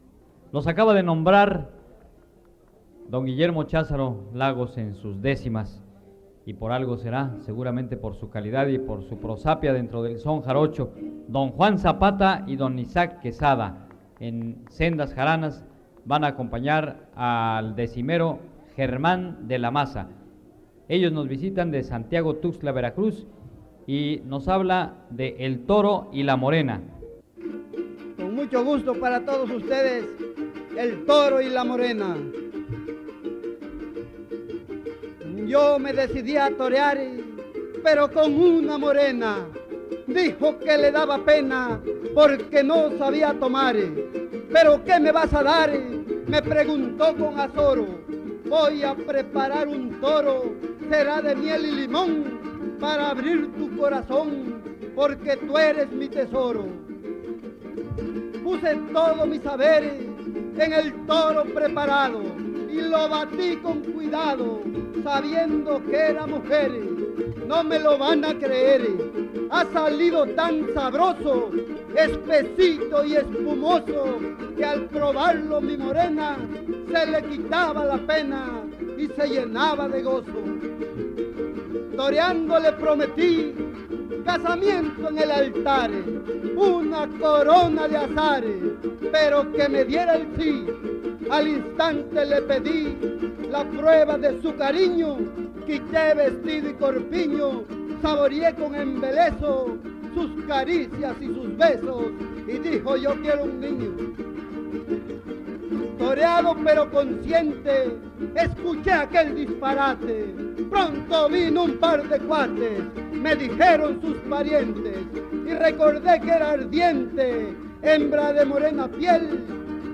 Noveno Encuentro de jaraneros